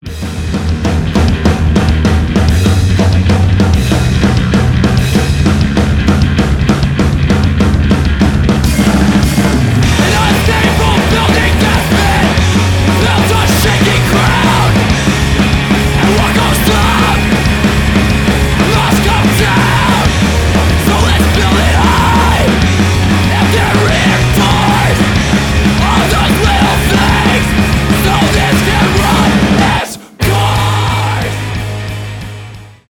панк-рок , post-hardcore
гитара , барабаны